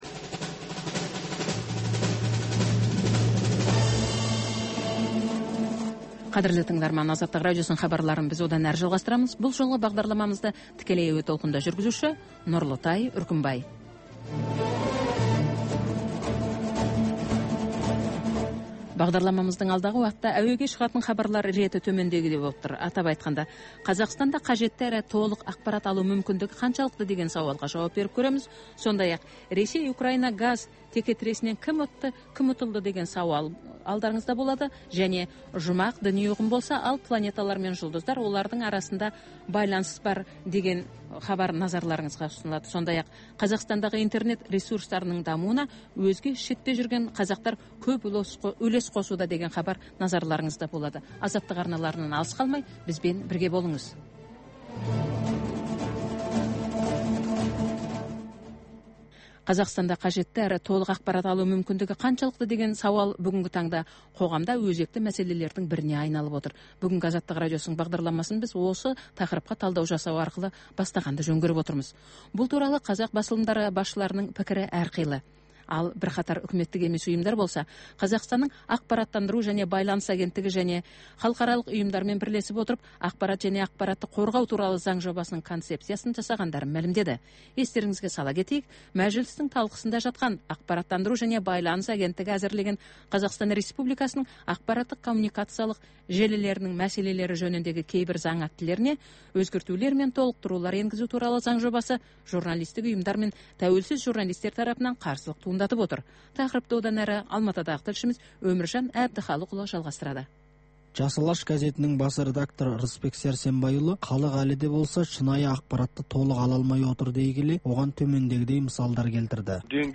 Бүгінгі күннің өзекті мәселесі, пікірталас, оқиға ортасынан алынған репортаж, қазақстандық және халықаралық сарапшылар пікірі, баспасөзге шолу.